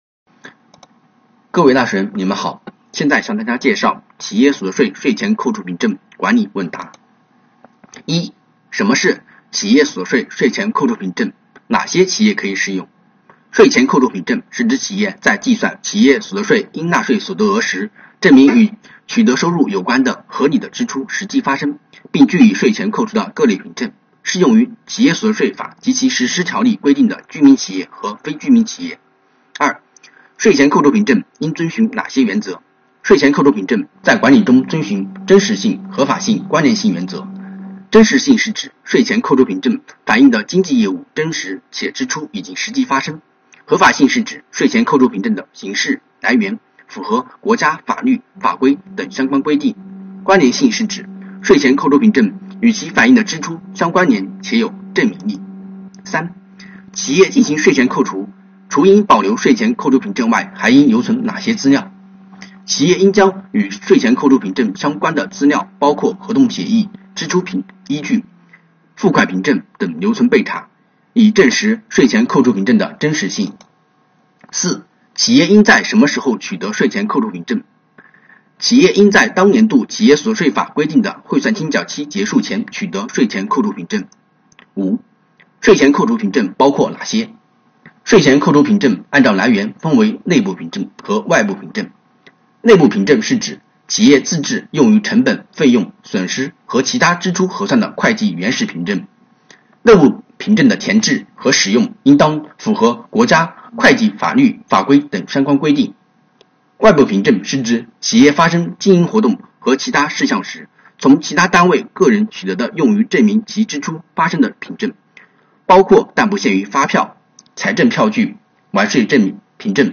主讲人：奉贤区税务局